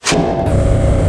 power_generators_electric_engine_startup.ogg